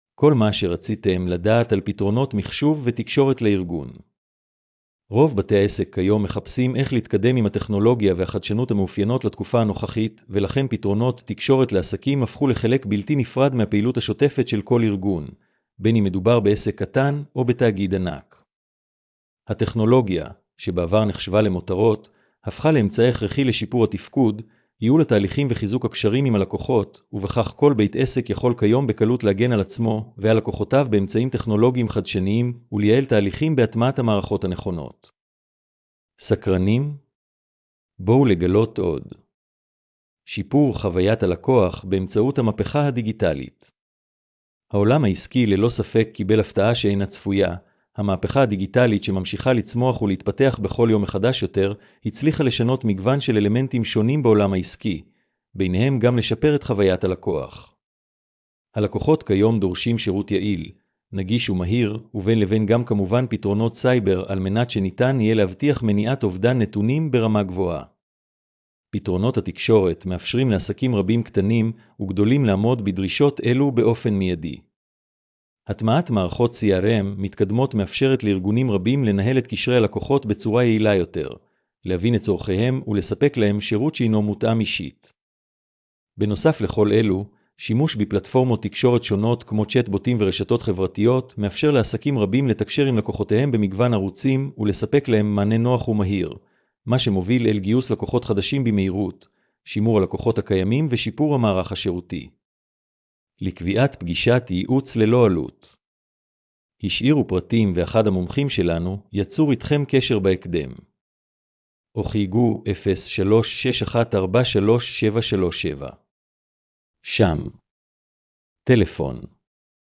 הקראת המאמר לאנשים עם מוגבלות: